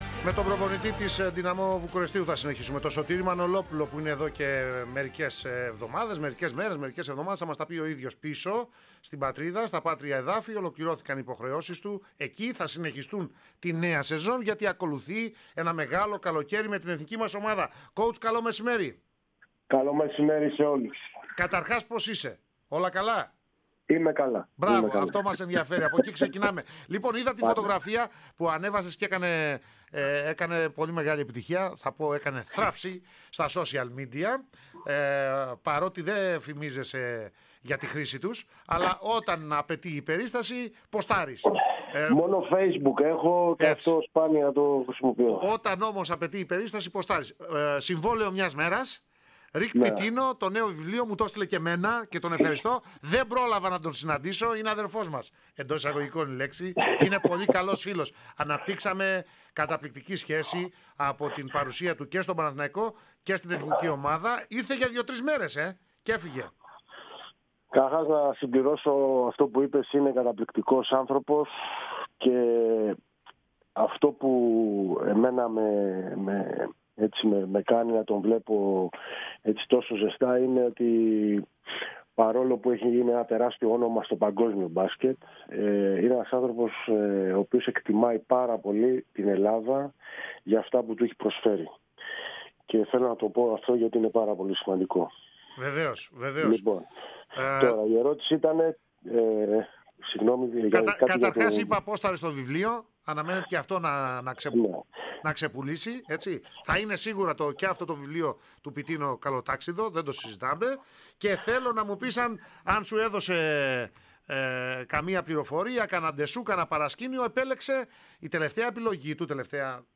Α. Ακούστε τη συνέντευξη στην ΕΡΑ ΣΠΟΡ: https